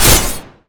sven_attack4_mvRKU0d.mp3